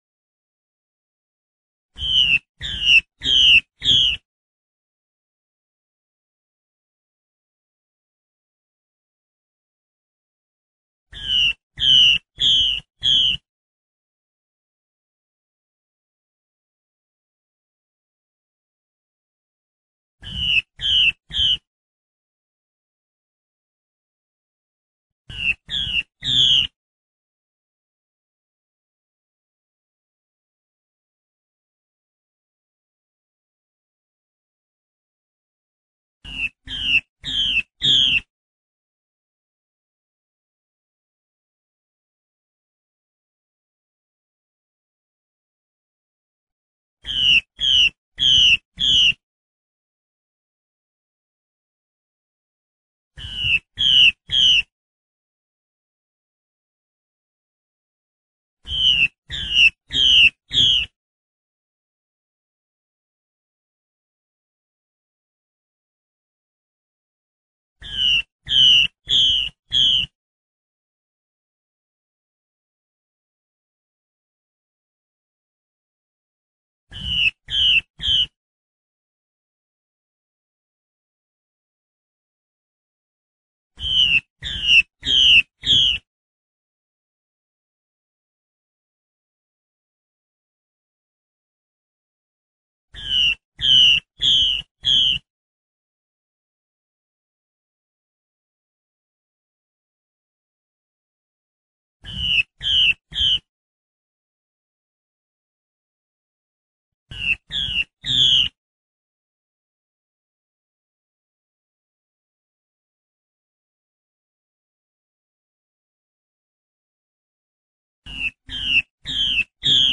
画眉鸟叫声mp3
母画眉鸟叫声，mp3格式的，还真不错的。
huameiniao.mp3